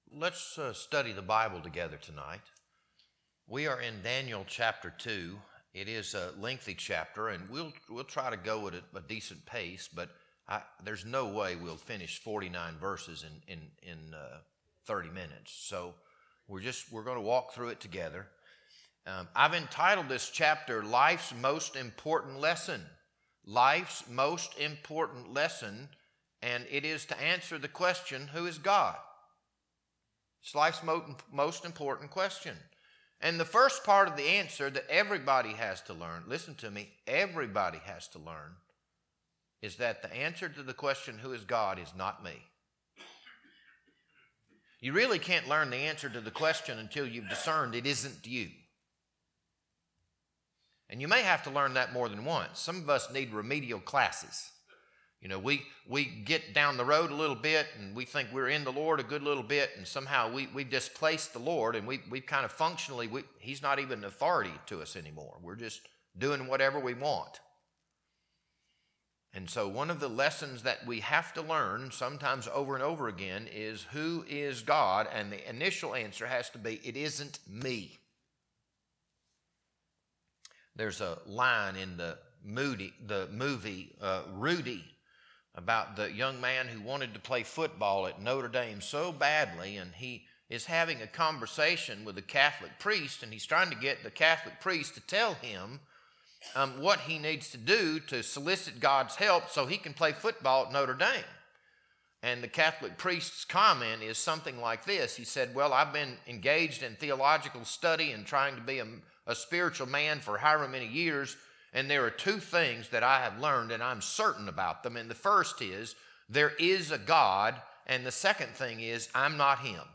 This Sunday evening sermon was recorded on April 26th, 2026.